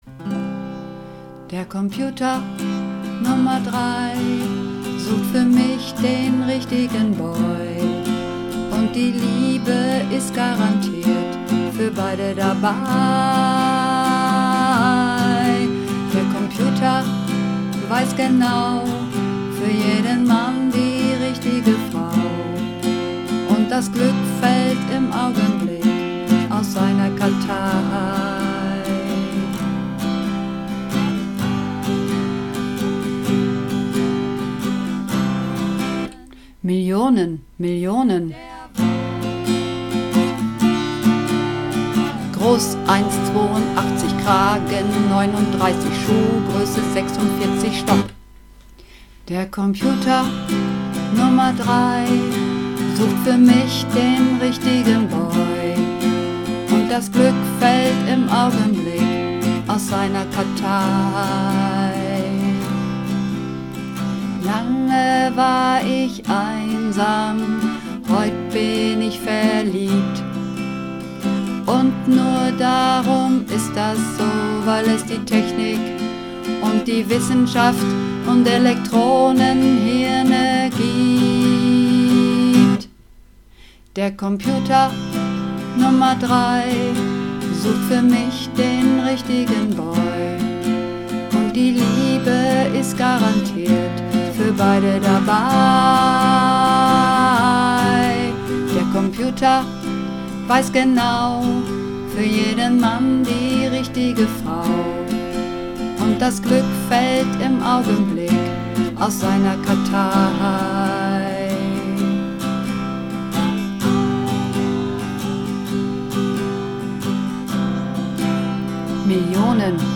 Übungsaufnahmen - Der Computer Nr. 3
Runterladen (Mit rechter Maustaste anklicken, Menübefehl auswählen)   Der Computer Nr. 3 (Bass)
Der_Computer_Nr_3__2_Bass.mp3